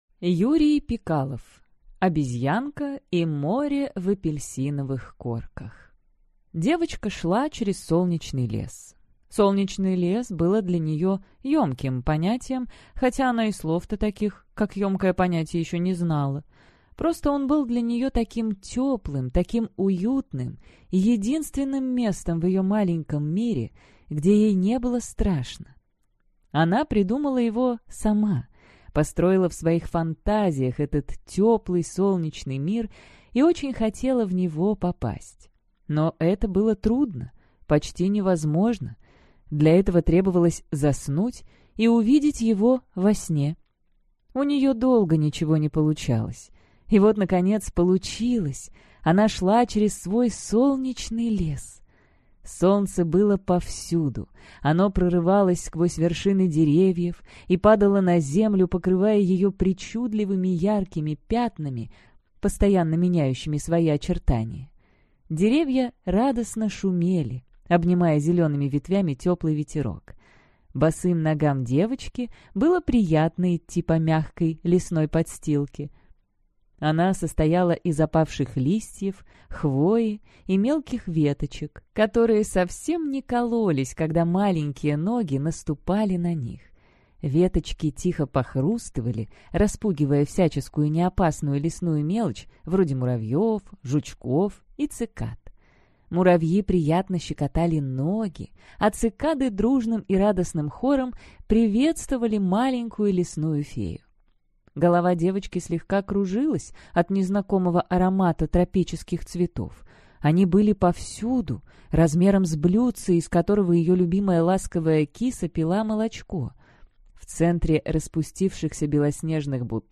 Аудиокнига Обезьянка и море в апельсиновых корках | Библиотека аудиокниг